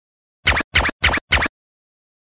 Звуки скретча